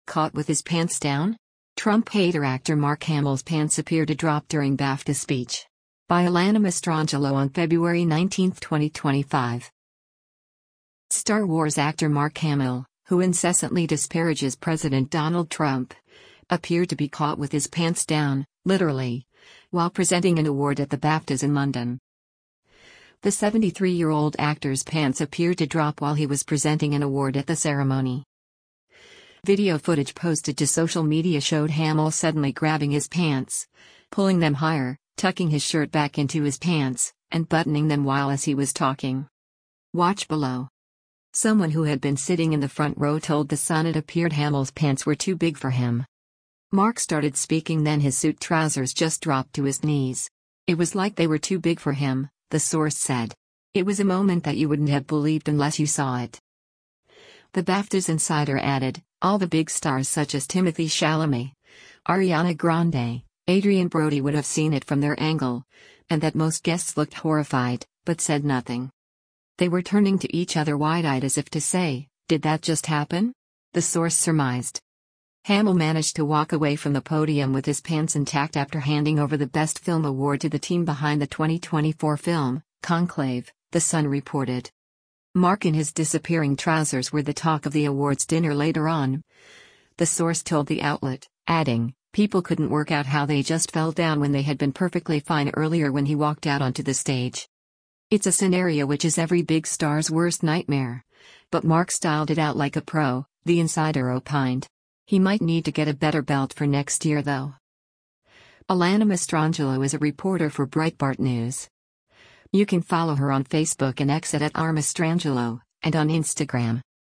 Caught with His Pants Down? Trump Hater Actor Mark Hamill's Pants Appear to Drop During BAFTA Speech
LONDON, ENGLAND - FEBRUARY 16: Mark Hamill presents the Best Film Award on stage during th